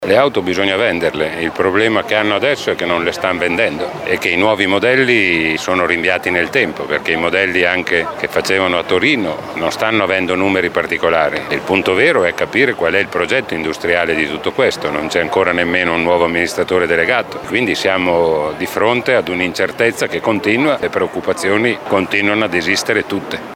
Tappa modenese ieri del segretario generale della Cgil Maurizio Landini che ha scelto la sede della Maserati per il comizio.
Sulla crisi Maserati le parole del segretario della Cgil Landini: